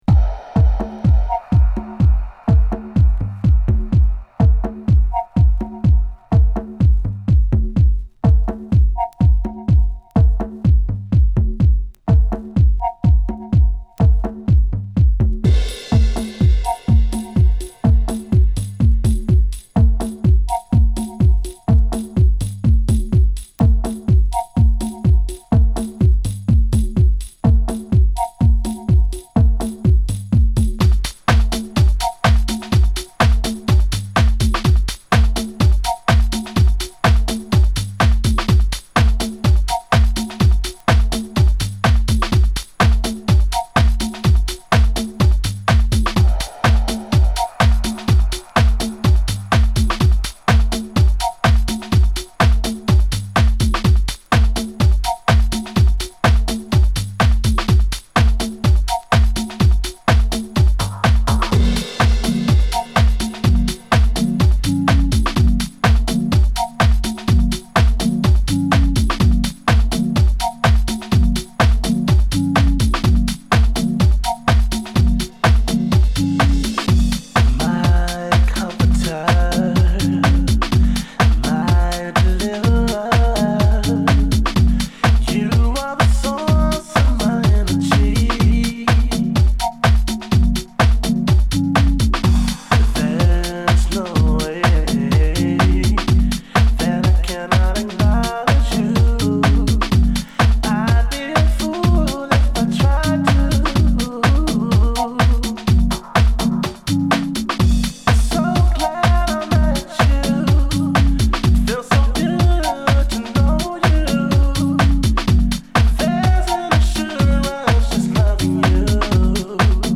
＊試聴はA→B1→B2です。